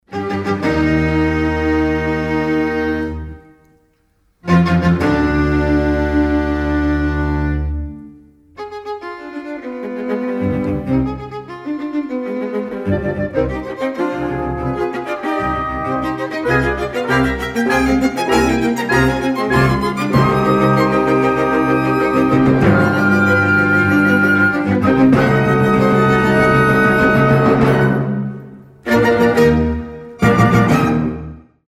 без слов
скрипка
оркестр